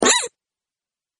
Звук удара молотком по кроту в игре